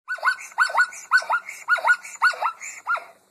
Звуки зебры
Зов зебры